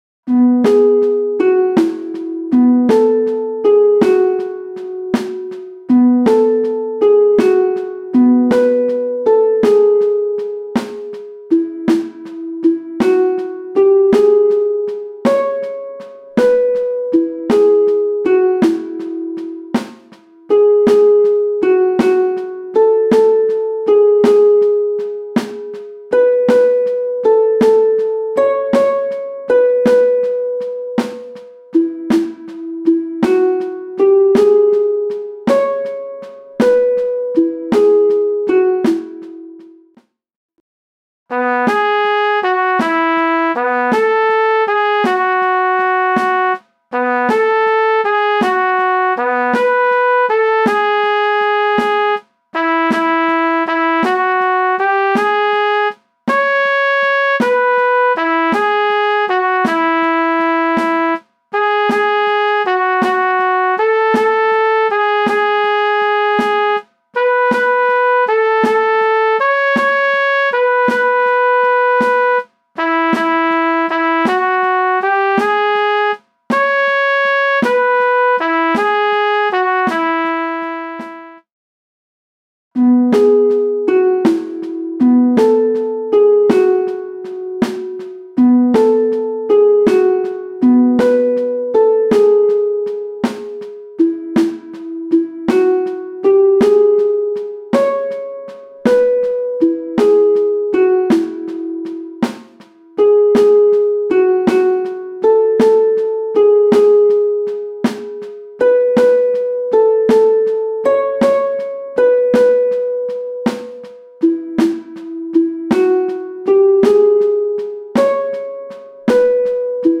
3/4 Takt
MIDI - 1-stimmig